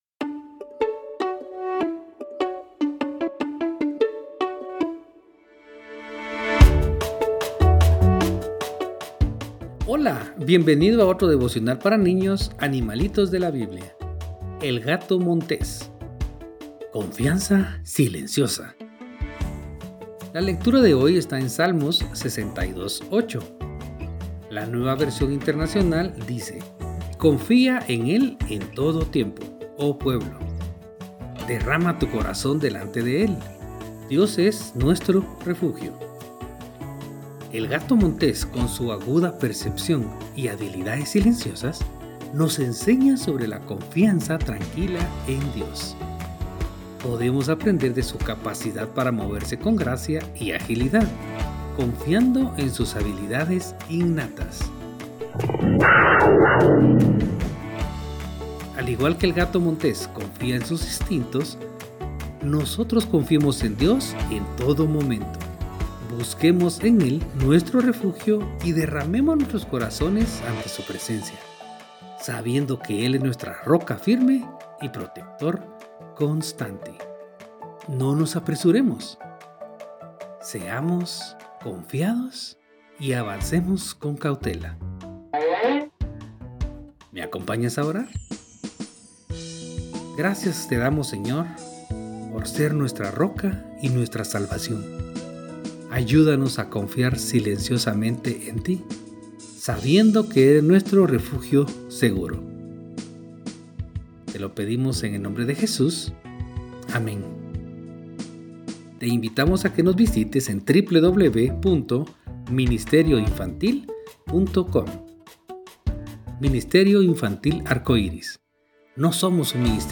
Animalitos de la Biblia – Devocionales Cortos para Niños